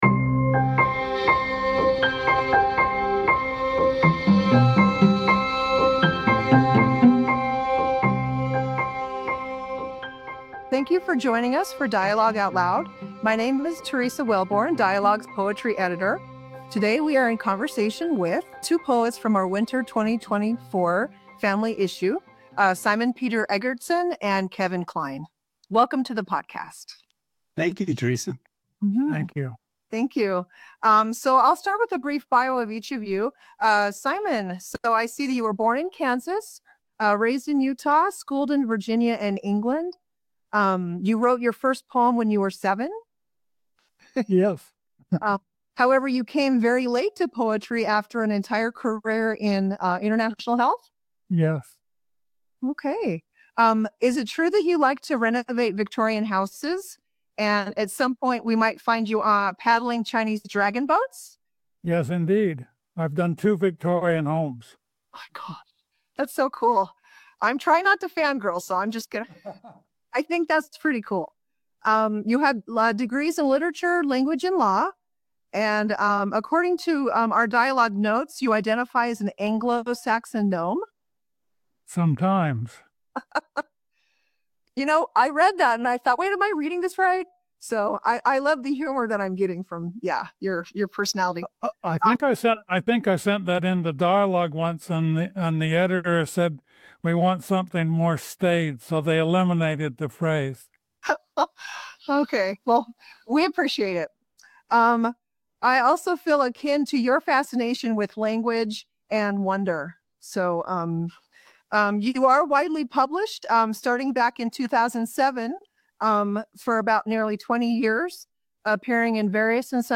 Poetry Beyond Ritual: A Conversation